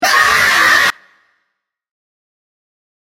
Scream